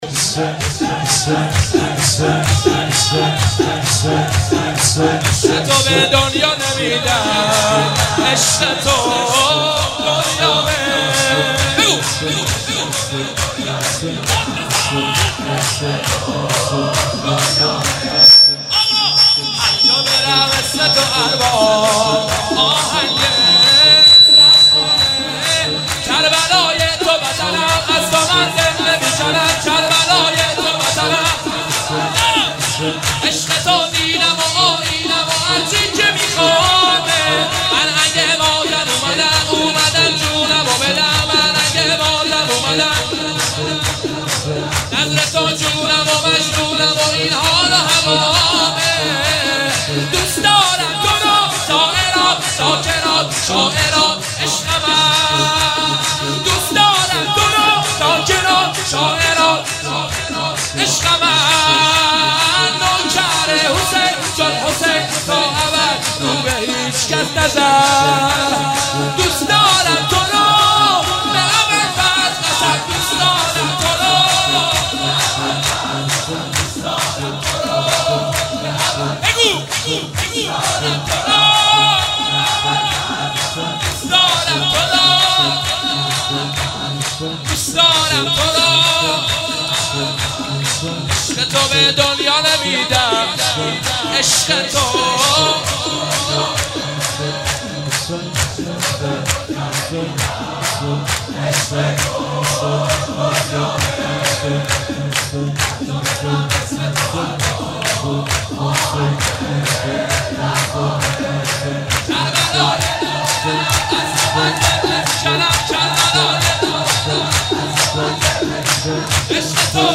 جشن نیمه شعبان/هیت روضه العباس(ع)